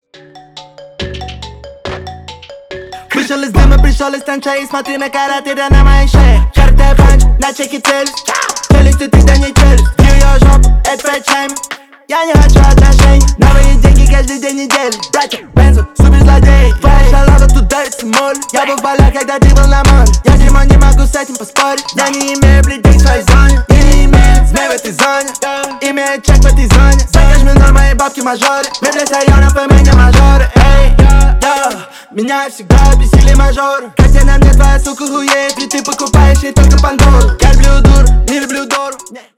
bass boosted
рэп , хип-хоп